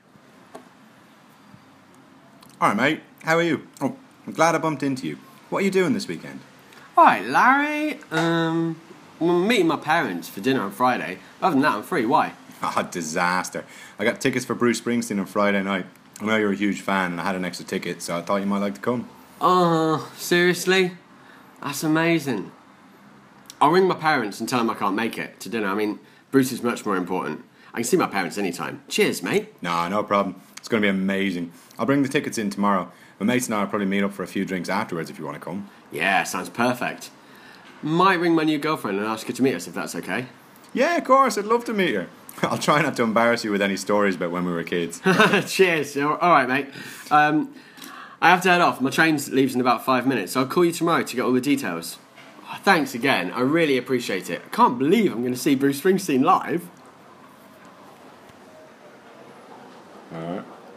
springsteen-conversation.m4a